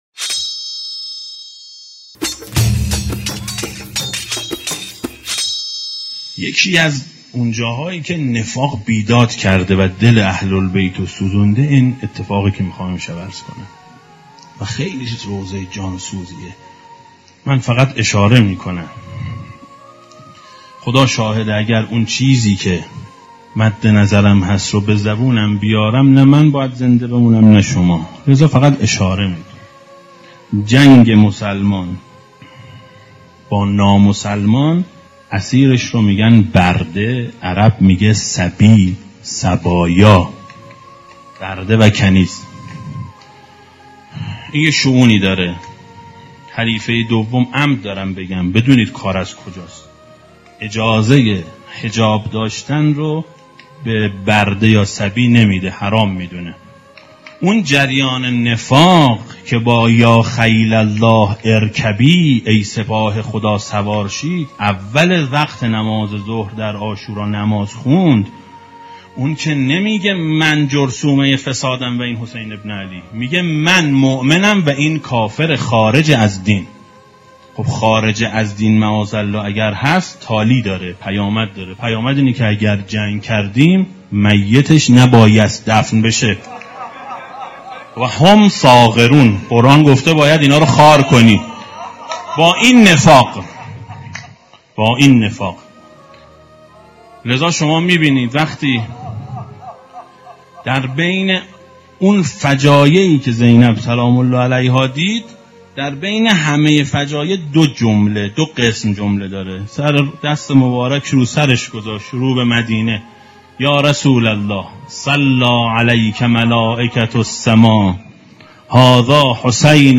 روضه شب سوم محرم سال 1394